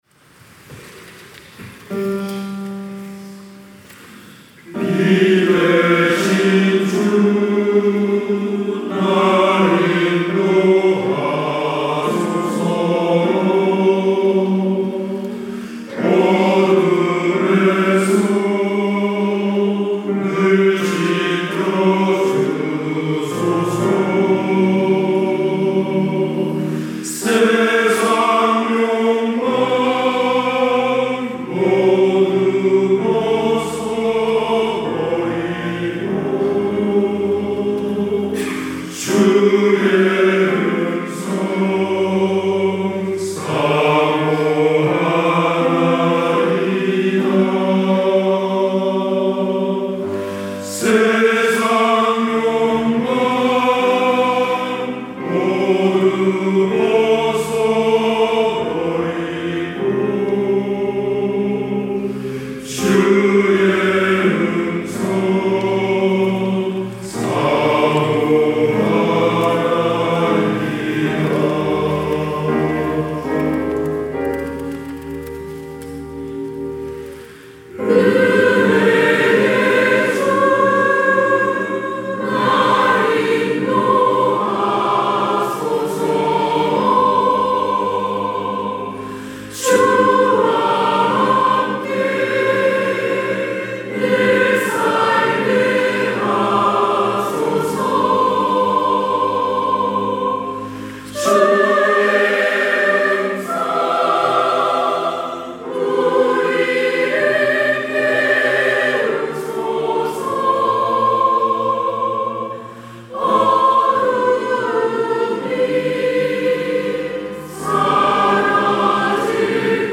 시온(주일1부) - 빛 되신 주님
찬양대